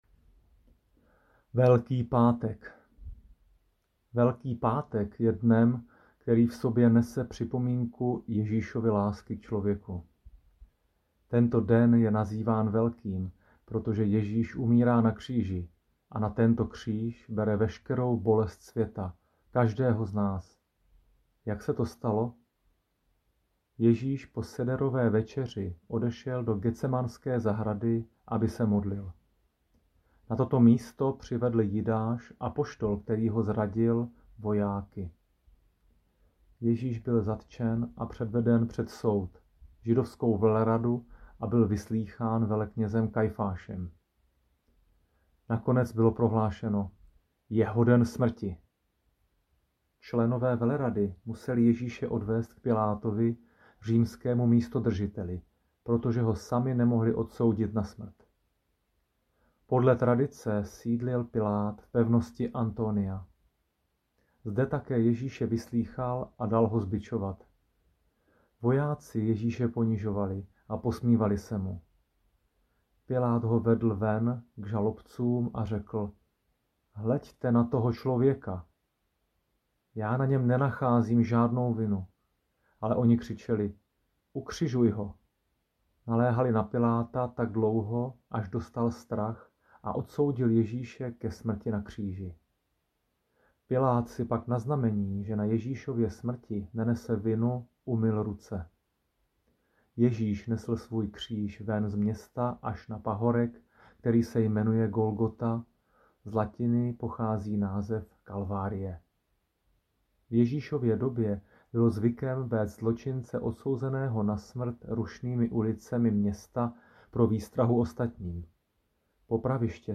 Lektorské úvody k triduu